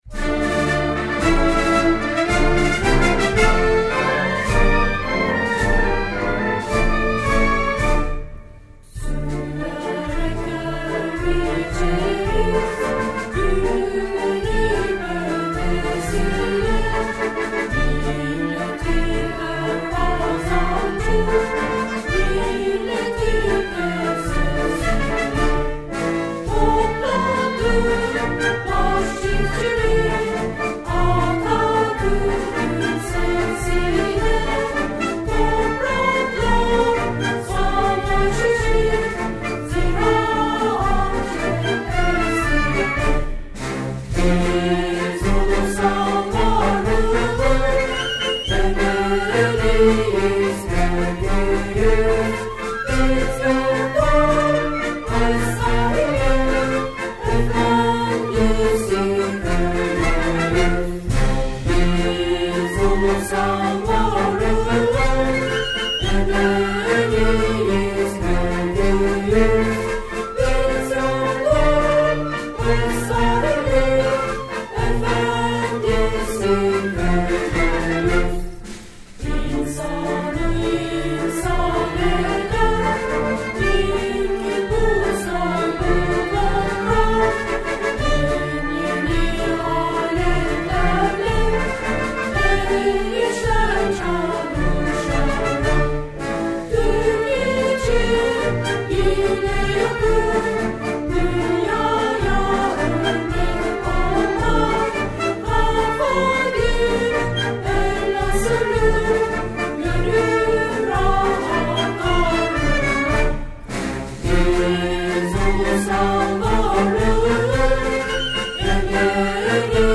ziraatmarsi.mp3